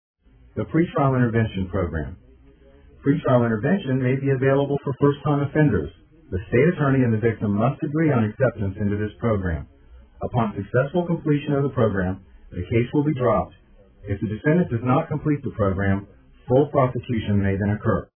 DUI Progression Described By a Board Certified Lawyer Go Over Each Step of a DUI in Tampa Courts